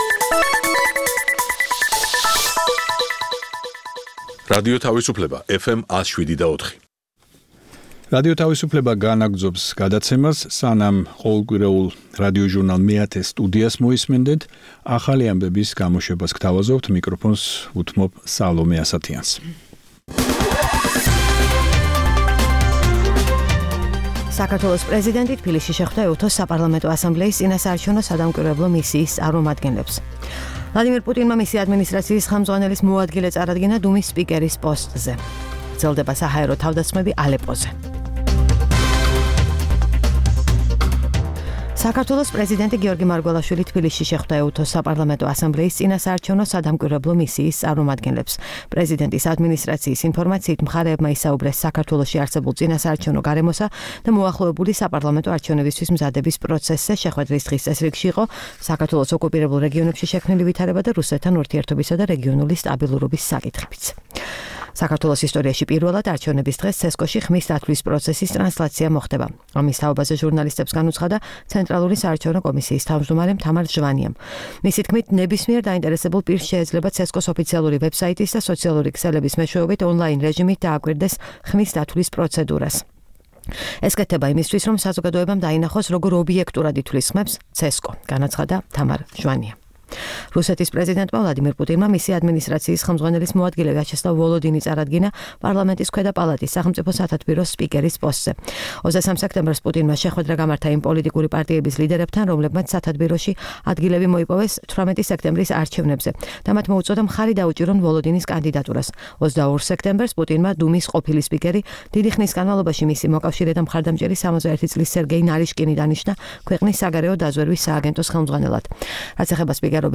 ეს პროგრამა ჩვენი ტრადიციული რადიოჟურნალია, რომელიც ორი ათეული წლის წინათ შეიქმნა ჯერ კიდევ მიუნხენში - რადიო თავისუფლების ყოფილ შტაბ-ბინაში, სადაც ქართული რედაქციის გადაცემების ჩასაწერად მე-10 სტუდია იყო გამოყოფილი. რადიოჟურნალი „მეათე სტუდია“ მრავალფეროვან თემებს ეძღვნება - სიუჟეტებს პოლიტიკასა და ეკონომიკაზე, გამოფენებსა და ფესტივალებზე, ინტერვიუებს ქართველ და უცხოელ ექსპერტებთან და ხელოვანებთან.